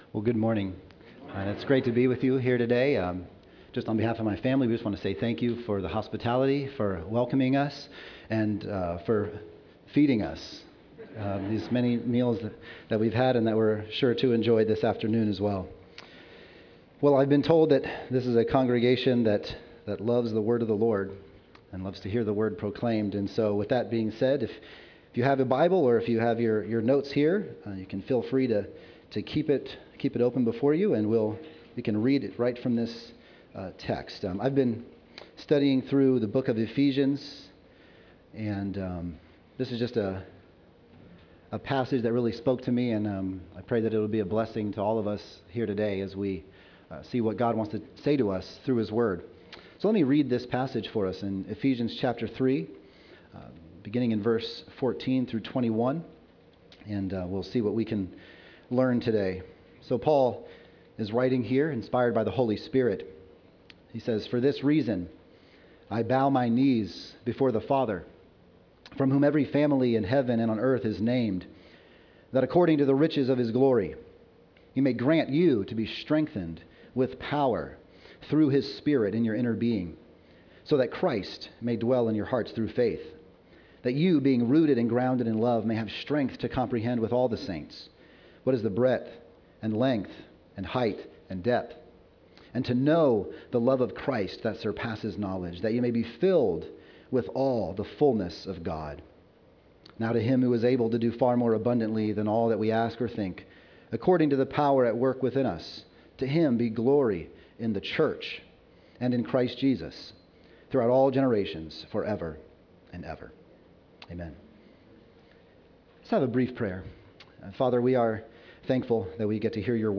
Preachers